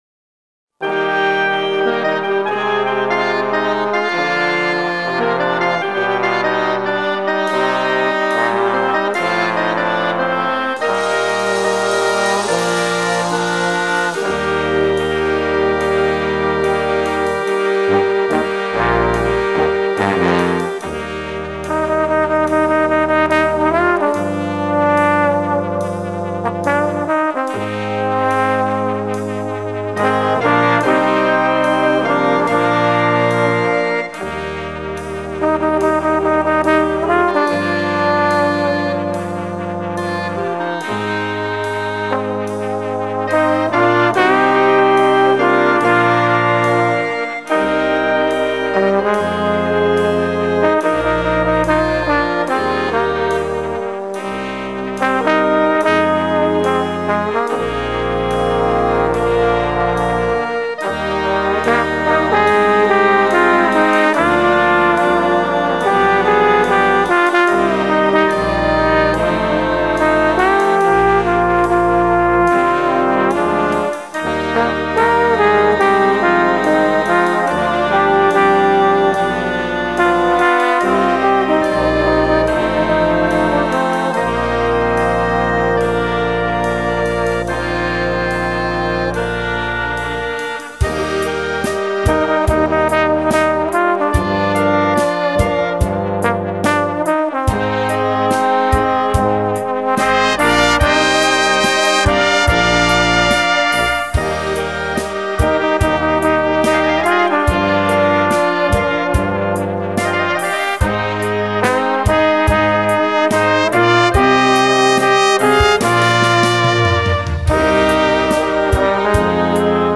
Dychová hudba
Inštrumentalne
Slow
Tenor/Pozauna